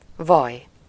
Ääntäminen
Ääntäminen France: IPA: [lø bœʁ] Tuntematon aksentti: IPA: /bœʁ/ Haettu sana löytyi näillä lähdekielillä: ranska Käännös Ääninäyte Substantiivit 1. vaj Suku: m .